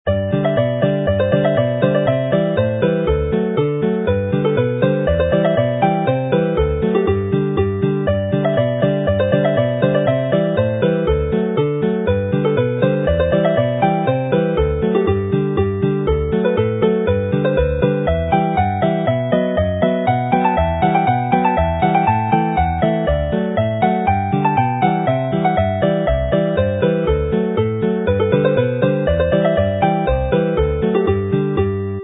mp3 file as a polka, fast with chords